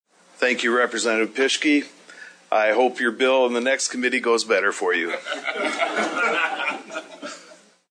Committee Chairman Mark Willadsen, a Sioux Falls Republican, had the final word.